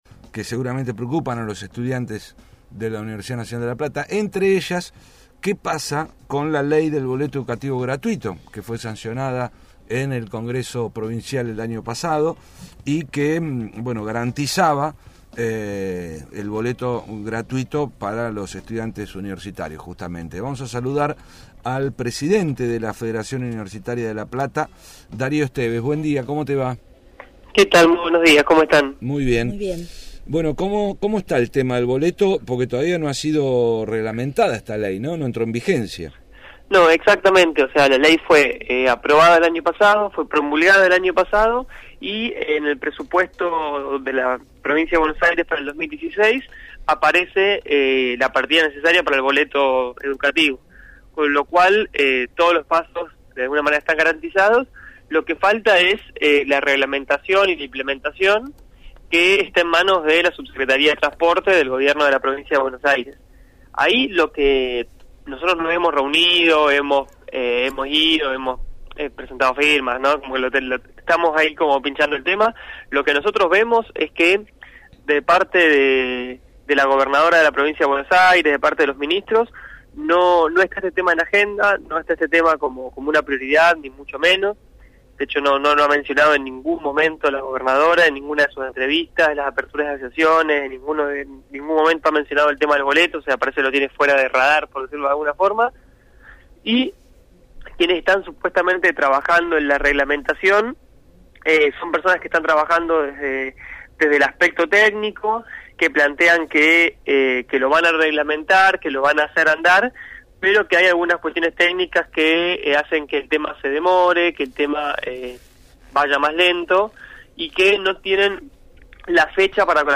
dialogó